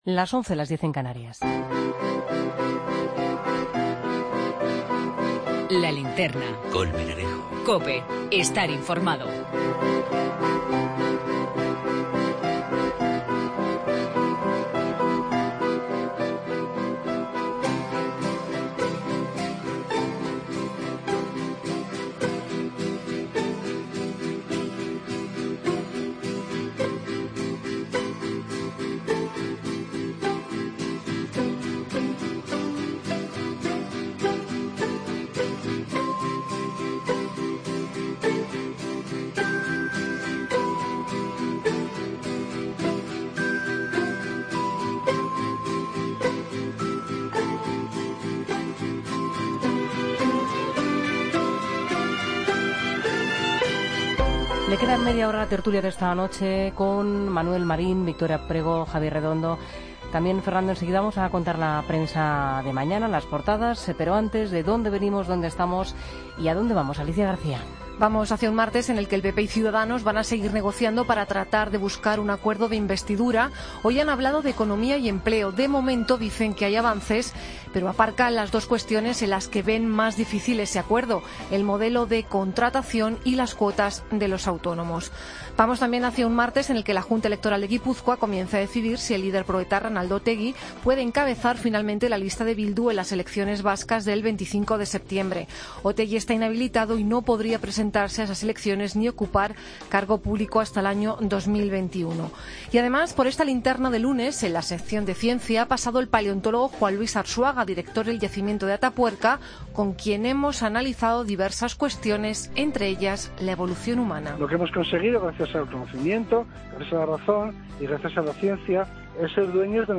Tertulia II, lunes 22 de agosto de 2016